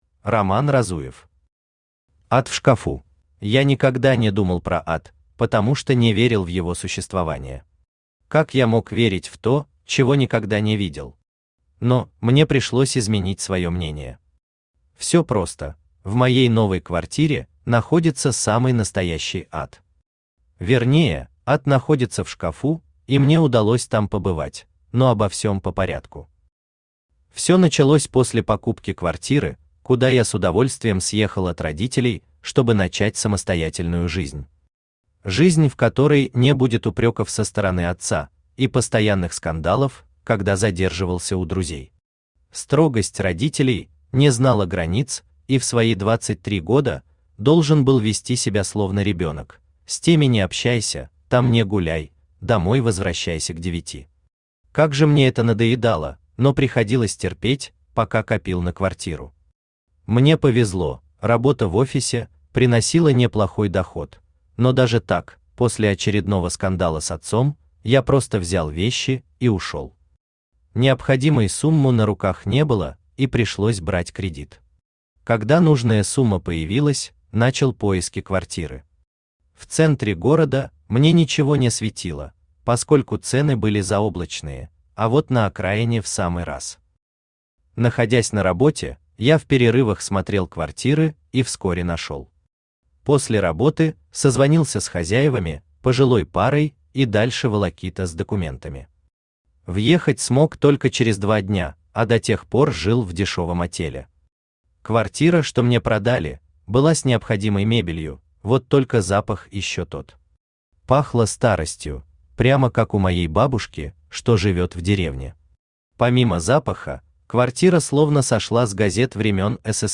Аудиокнига Ад в шкафу | Библиотека аудиокниг
Aудиокнига Ад в шкафу Автор RoMan Разуев Читает аудиокнигу Авточтец ЛитРес.